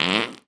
epic_bellow_02.ogg